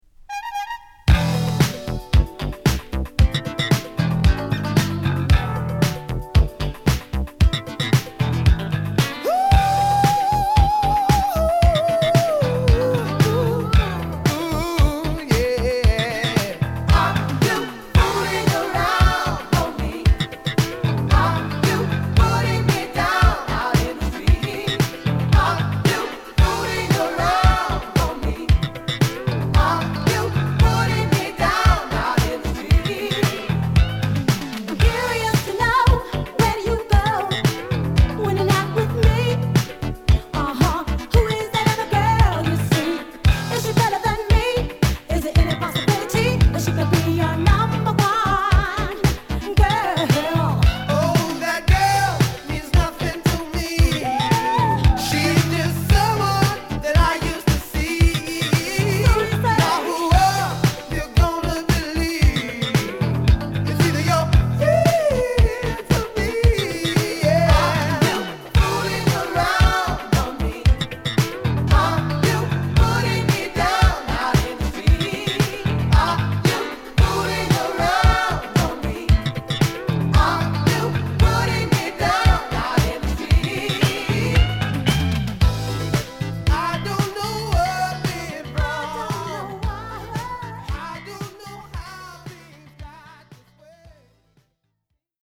こちらはパフパフホーンから、熱い男女の絡みを交えたディスコチューンを収録！